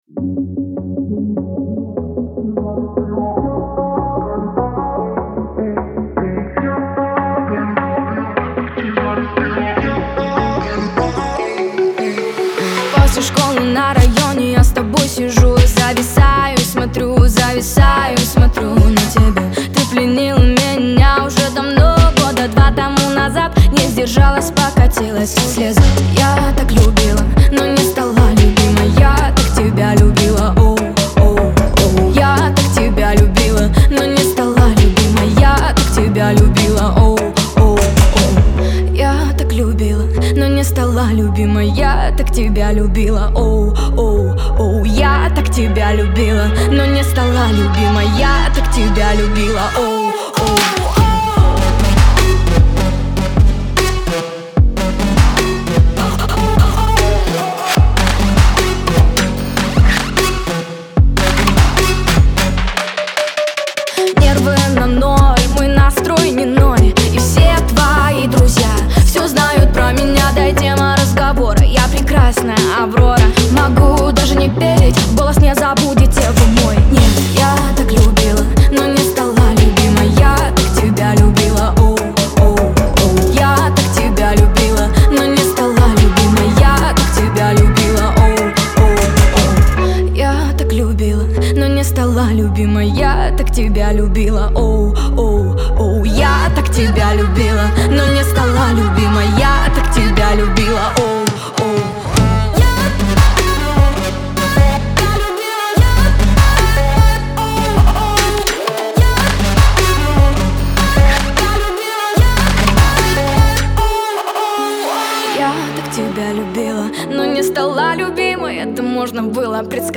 передавая каждую ноту с искренностью и страстью.